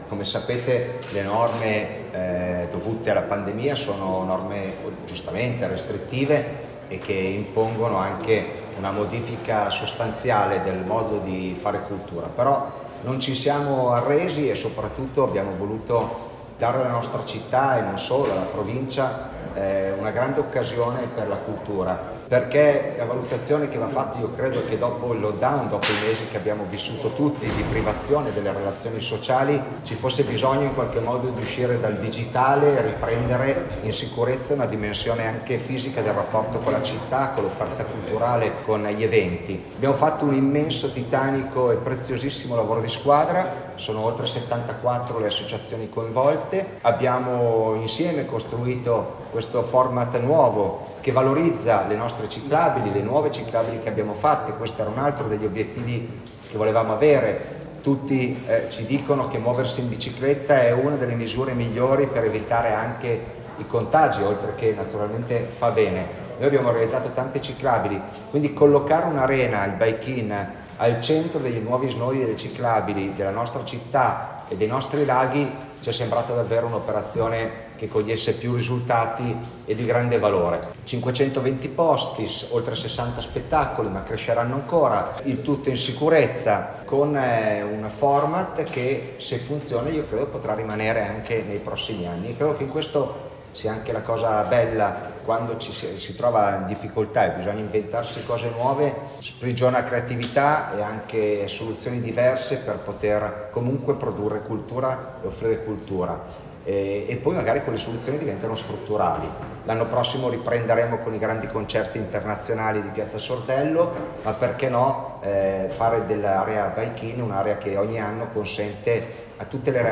il Sindaco di Mantova Mattia Palazzi